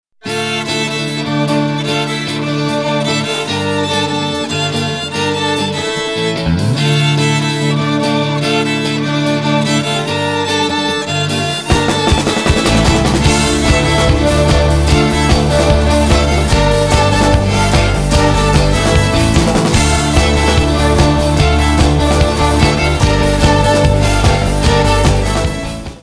Рок Металл
без слов